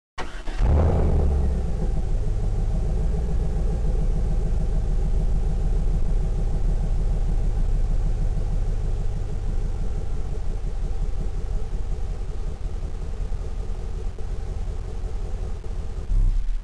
A nice mellow idel of the LS1 engine
Z28_idle.mp3